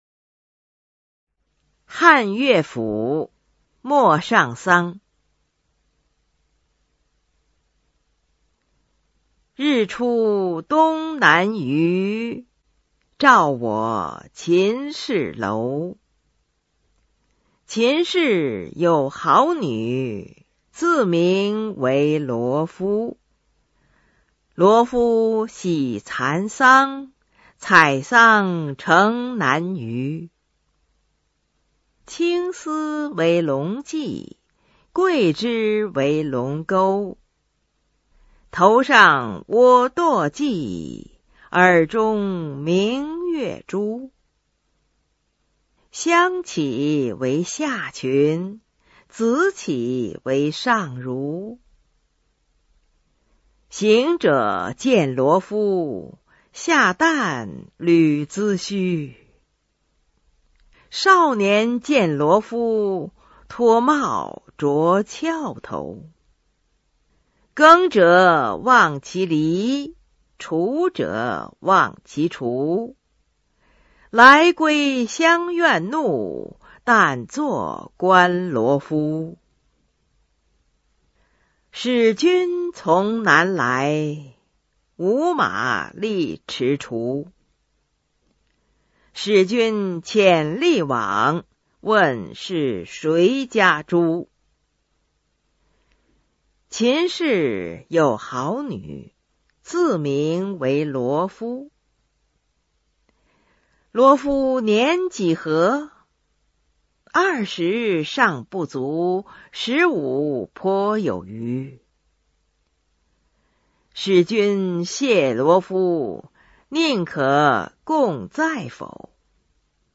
《陌上桑》原文和译文（含赏析、朗读）　/ 佚名